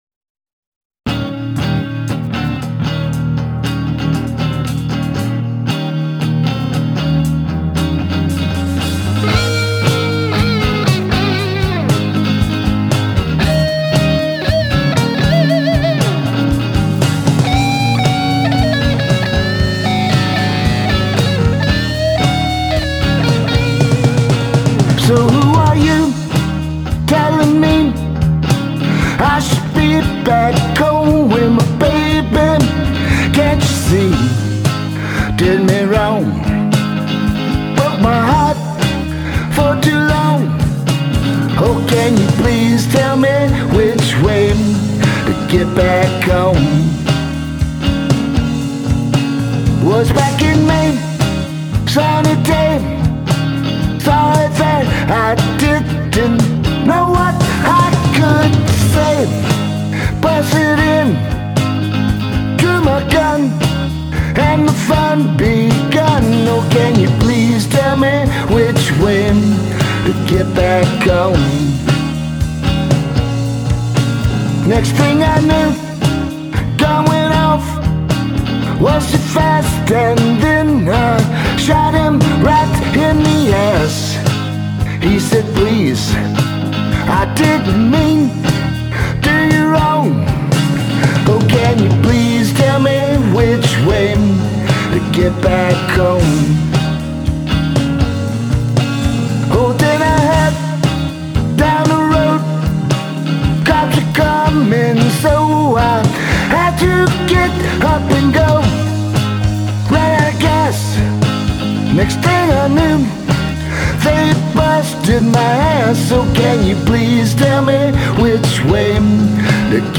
Genre: Folk Rock, Blues, Americana